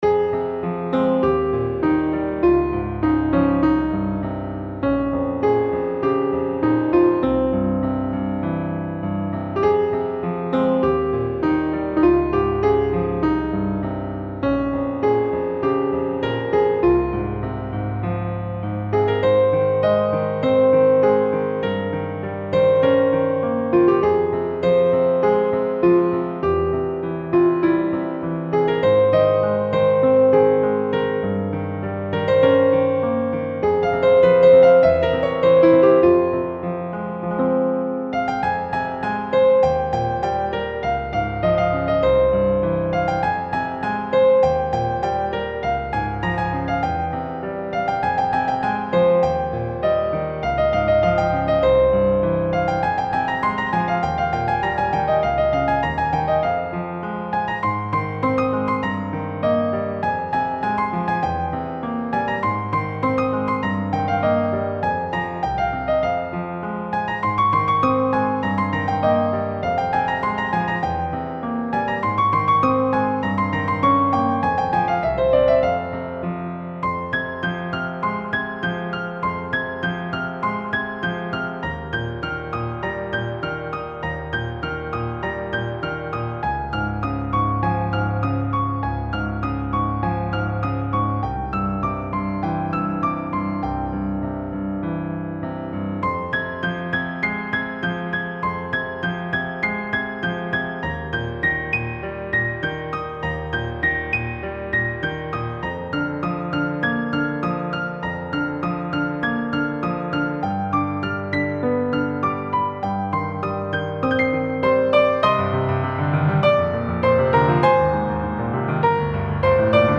Op2 Nr1 - Piano Music, Solo Keyboard